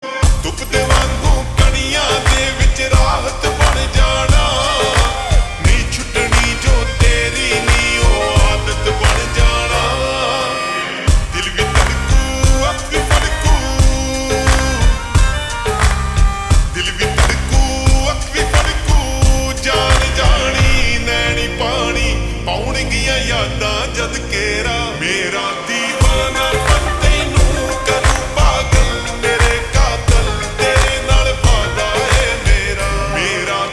(Slowed + Reverb)
At the forefront of contemporary Punjabi music